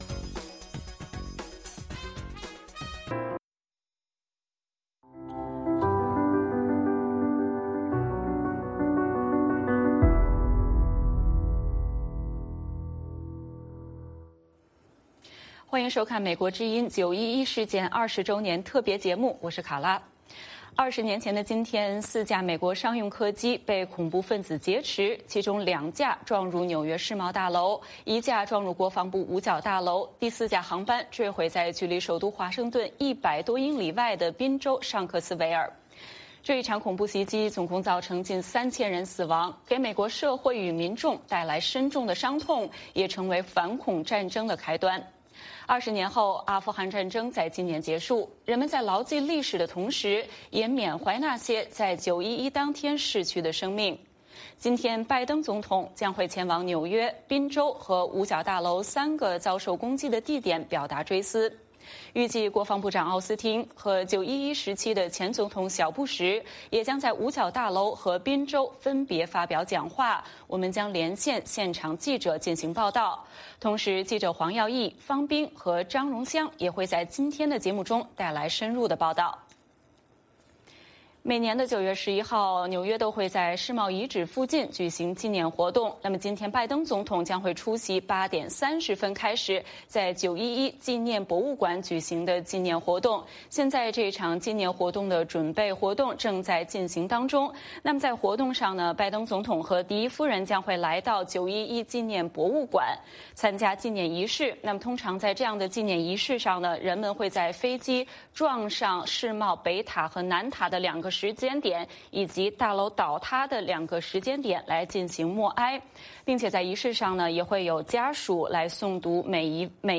9/11恐袭20周年之际，全美范围内将举办祈祷、静思、鸣钟、烛光等纪念活动缅怀遇难者，美国之音特别节目于9月11日美东时间周六早上8点开始，现场直播纽约、华盛顿、宾州等地的活动。